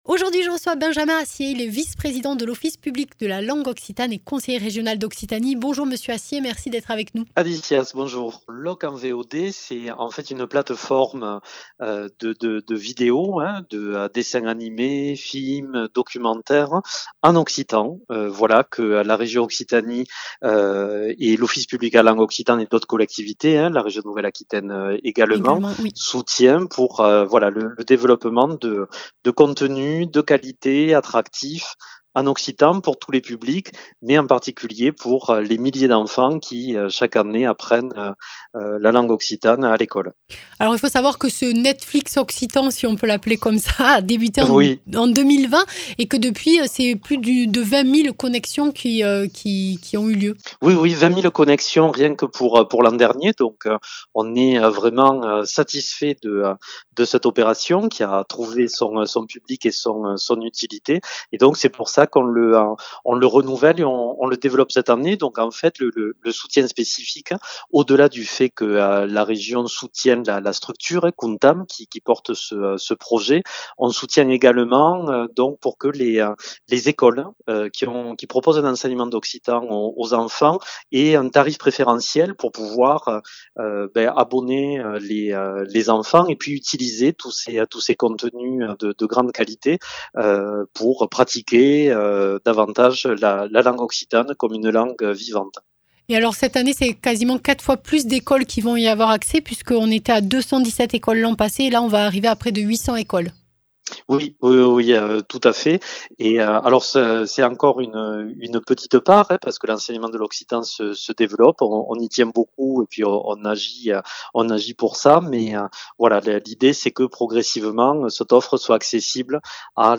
Interviews
Invité(s) : Benjamin Assié, vice-président de l’office public de la langue occitane et conseiller régional d’Occitanie